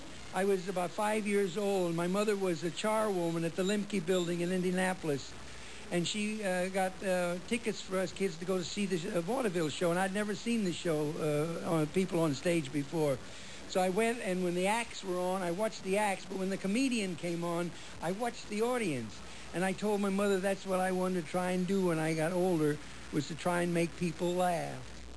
Click to hear in his own words when